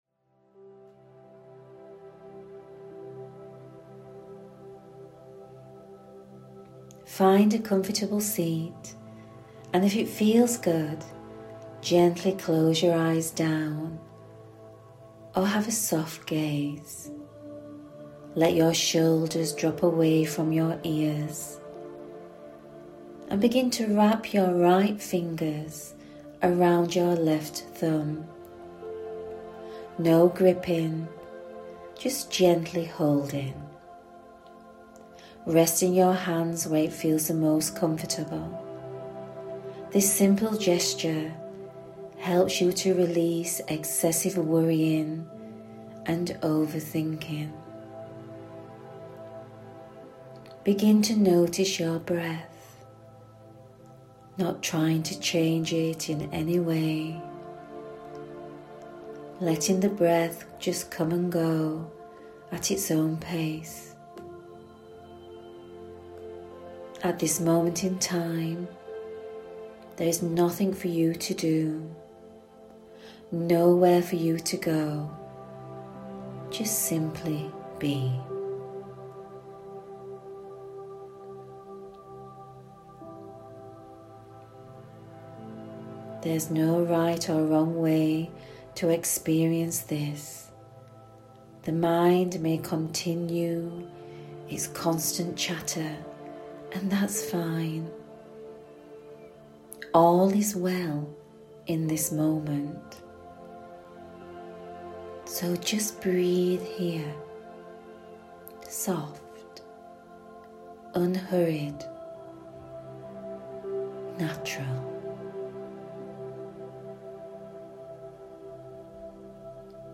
Try this Touch Yoga™ Minutes Audio Practice In this short audio practice we softly hold the thumb, this